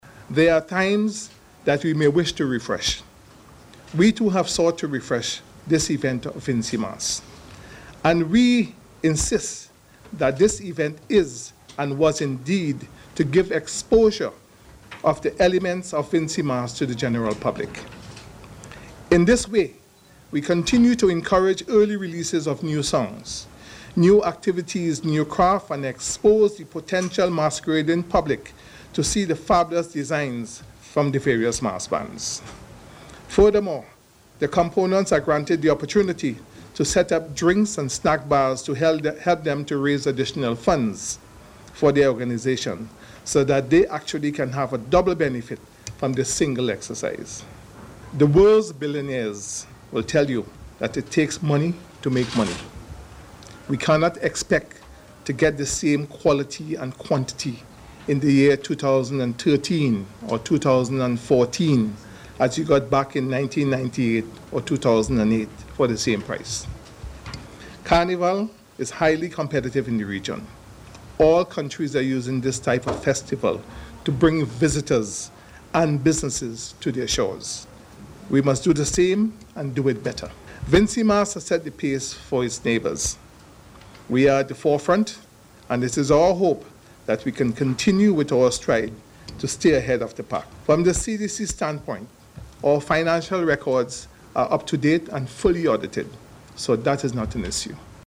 His remarks came at a news briefing on Tuesday when he said that some persons perceive that the launching should be scaled down to…